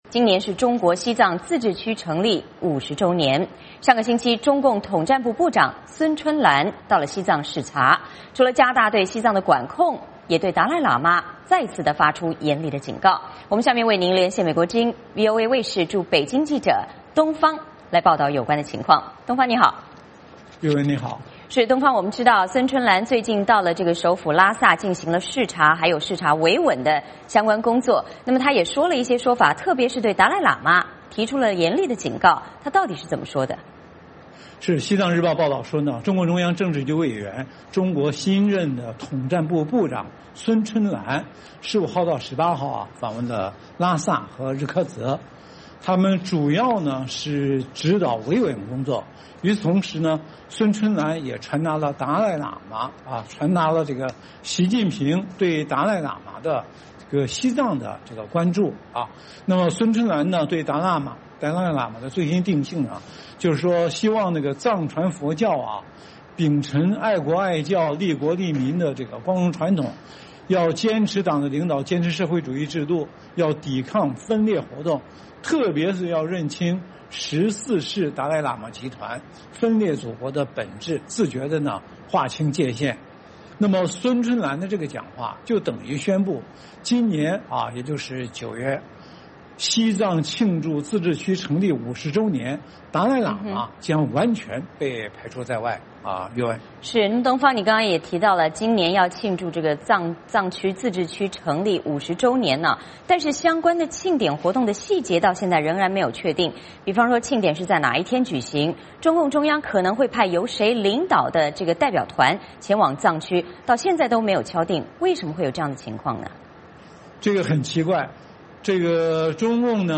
VOA连线：中国统战部长访西藏，呼吁坚定不移跟党走